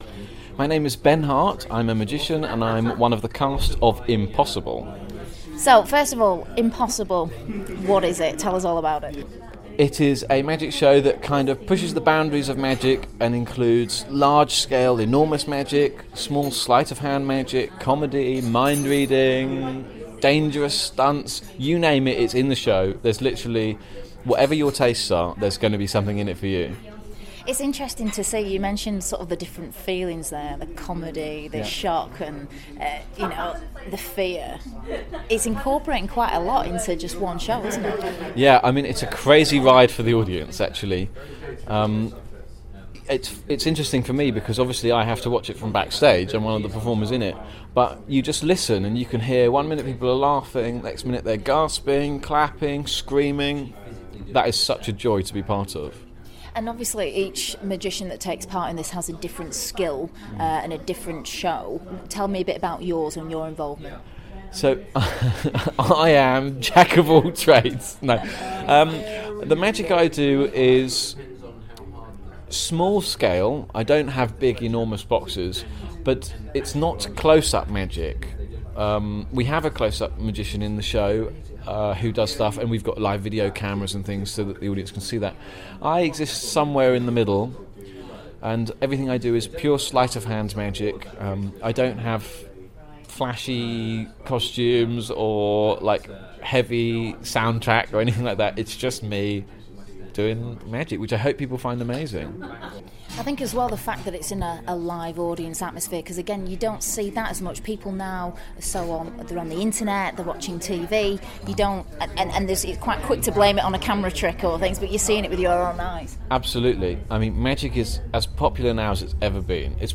The 'Impossible' show's Ben Hart speaks to Key 103